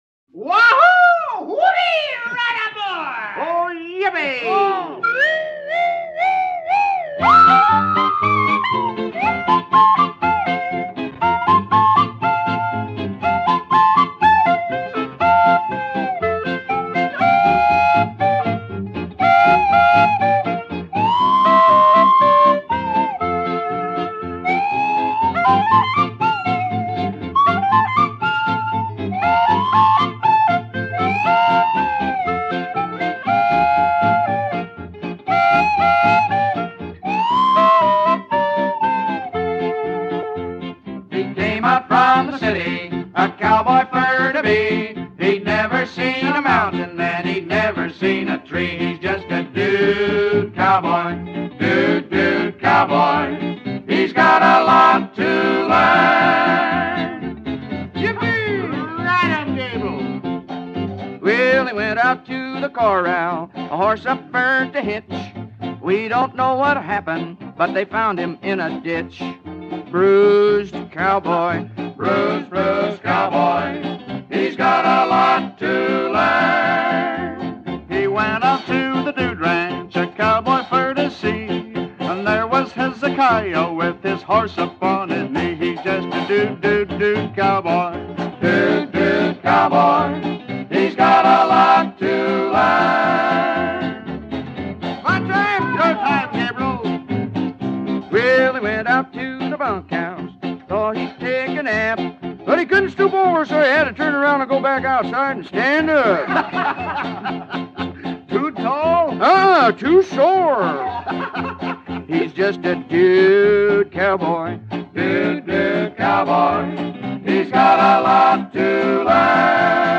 Немного кантри